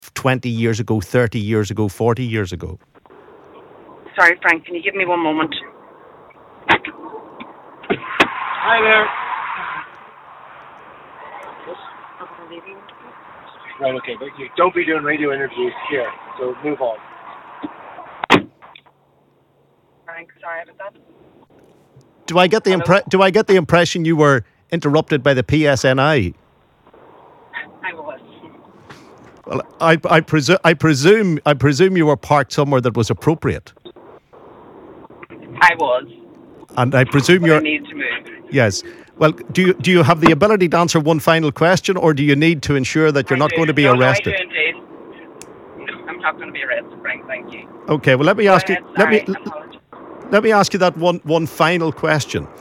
after being confronted by police live on air